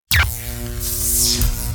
factorypowerdown.wav